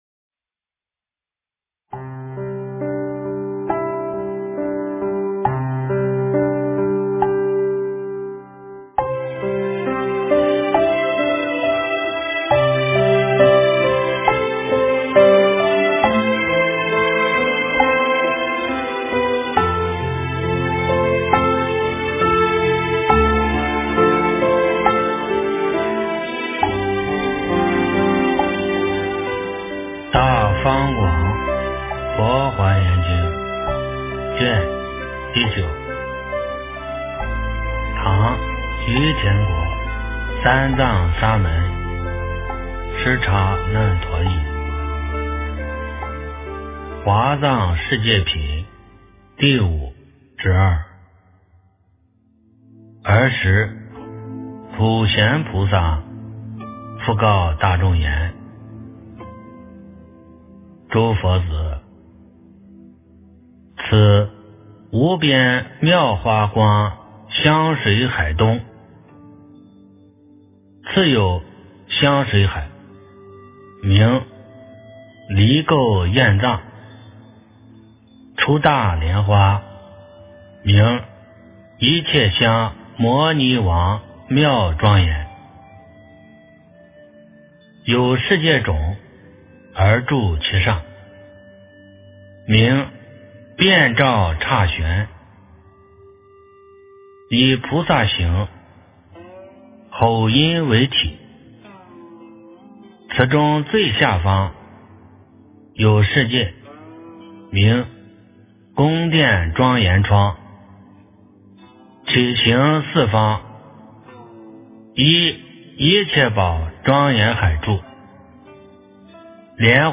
《华严经》09卷 - 诵经 - 云佛论坛